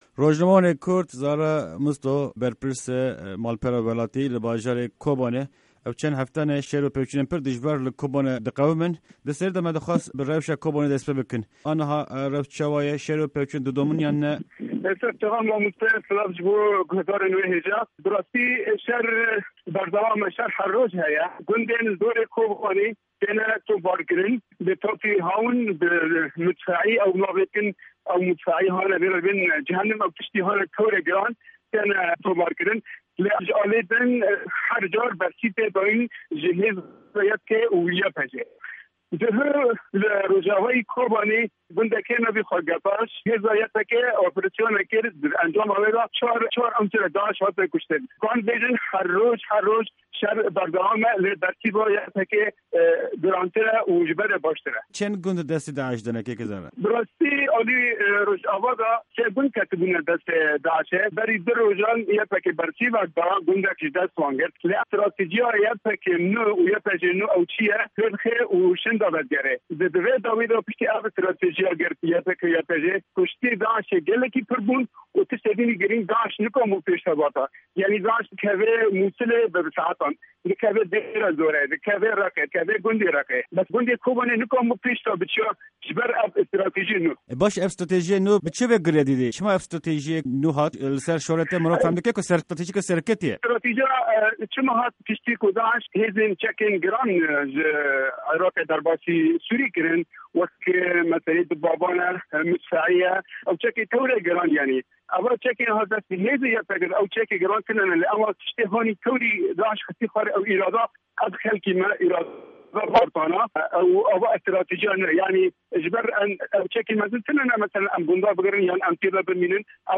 Di hevpeyvîna Dengê Amerîka de